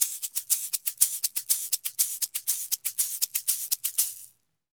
Eggs_ ST 120_3.wav